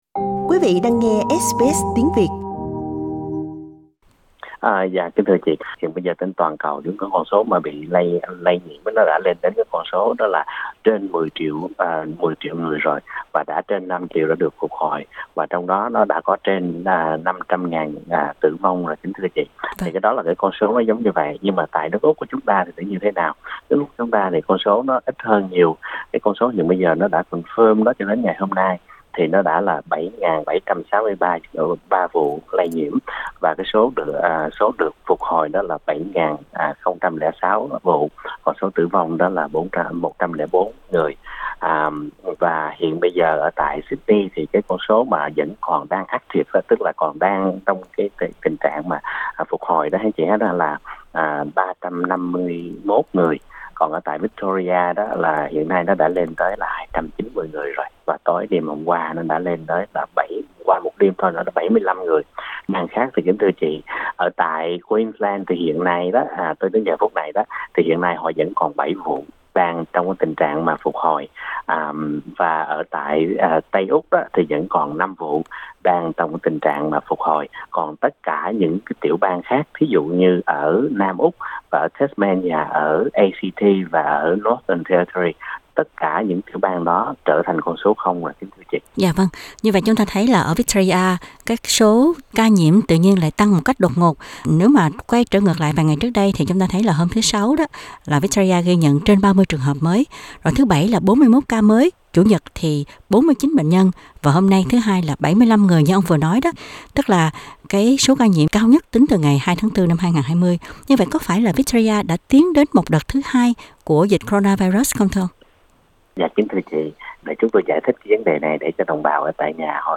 Trong phần phỏng vấn